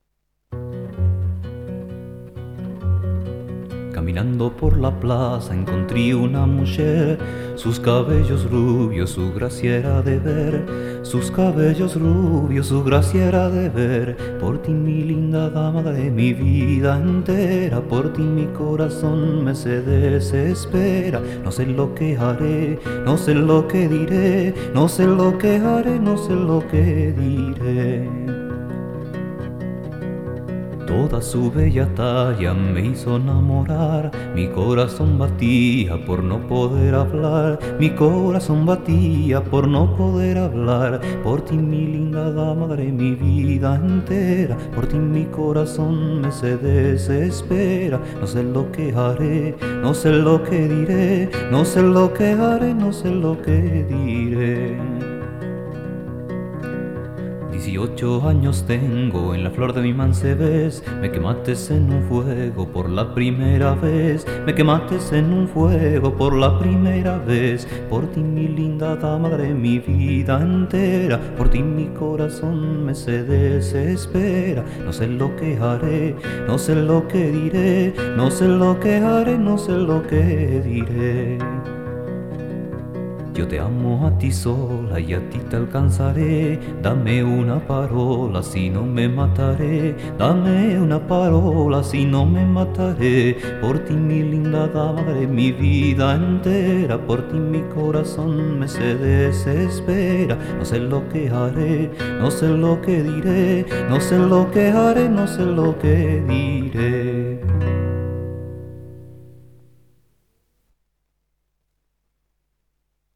Guitarra y voz